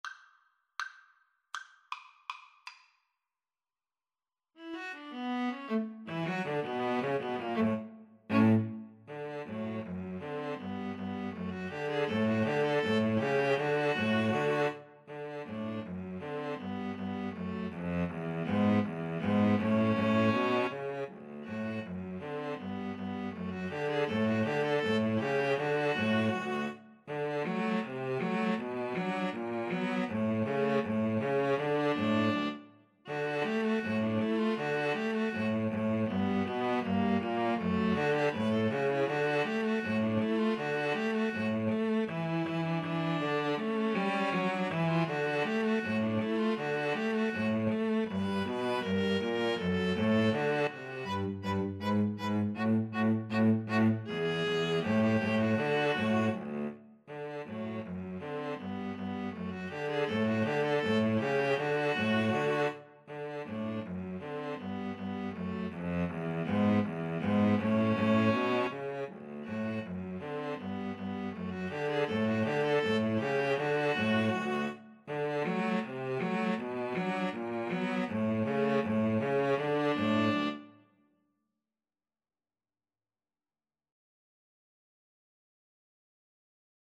Play (or use space bar on your keyboard) Pause Music Playalong - Player 1 Accompaniment Playalong - Player 3 Accompaniment reset tempo print settings full screen
D major (Sounding Pitch) (View more D major Music for String trio )
=250 Presto (View more music marked Presto)
Jazz (View more Jazz String trio Music)